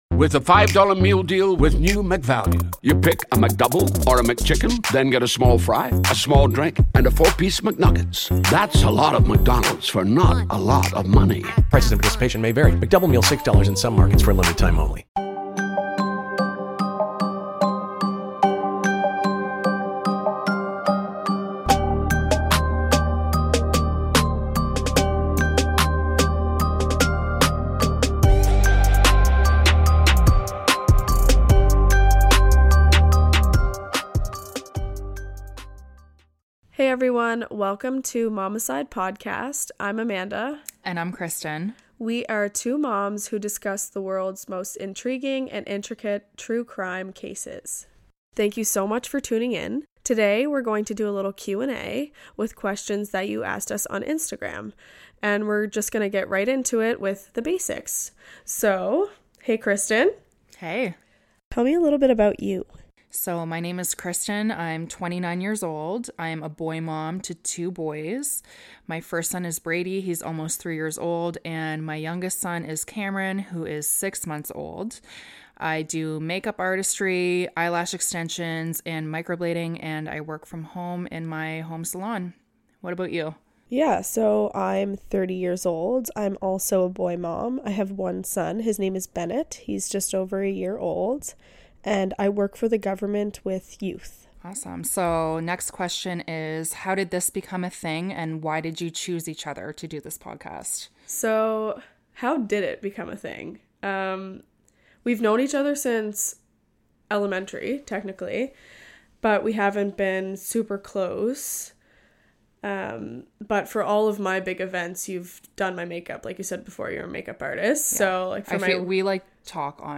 In a Q&A style episode